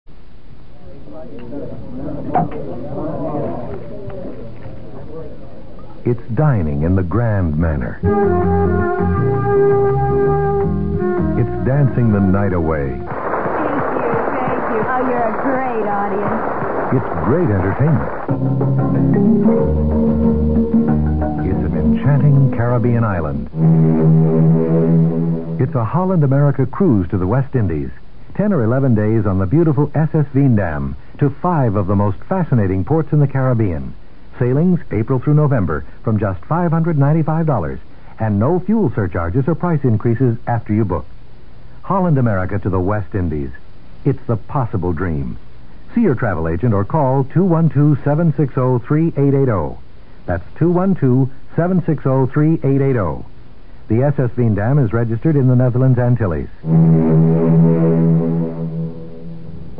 1970s Cruise Line Radio Commericals